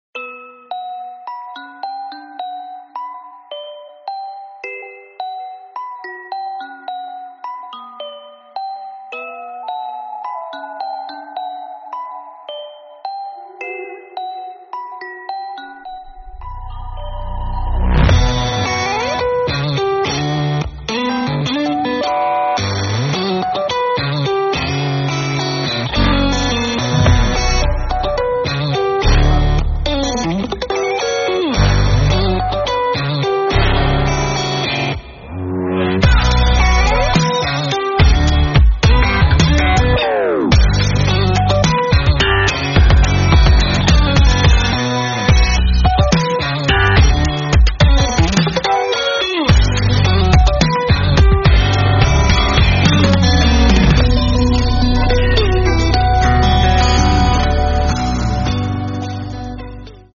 goat1.mp3